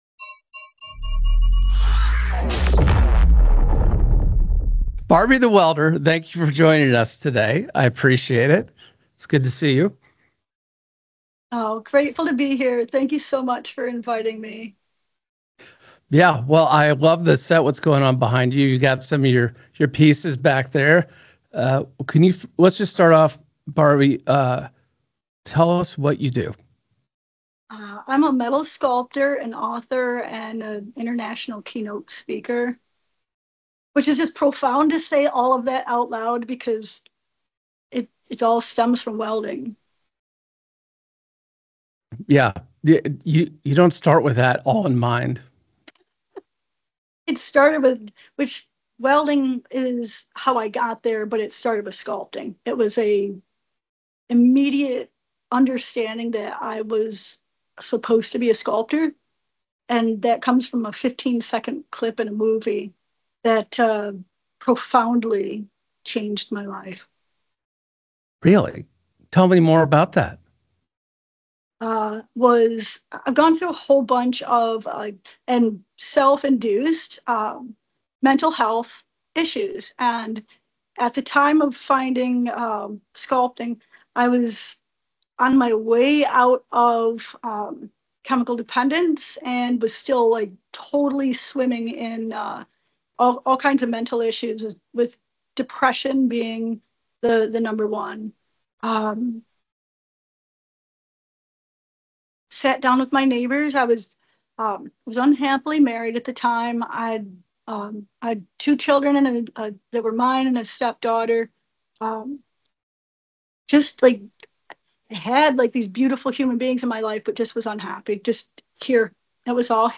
This conversation is filled with grit, positivity, and the reminder that we all have the power to change our lives and become more.